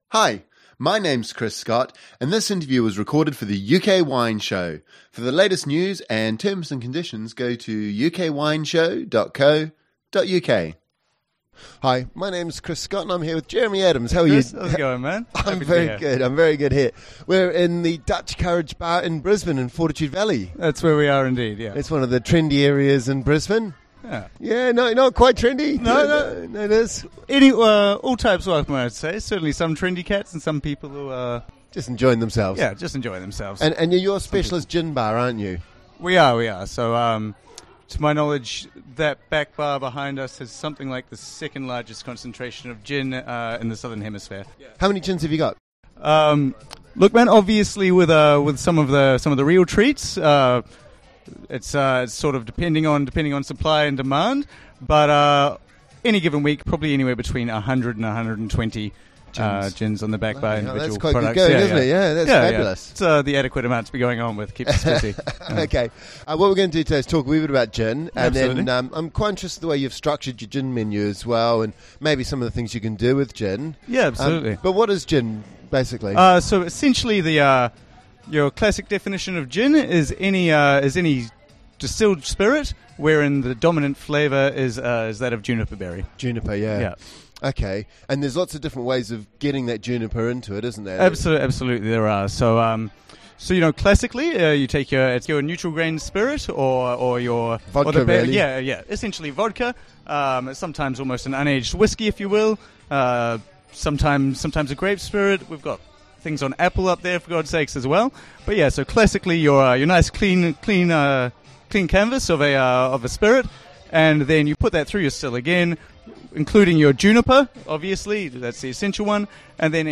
In this first interview we get into how gin is distilled and the methods of production. We also discuss the different styles and strengths and discover the story behind Gundpowder proof gin.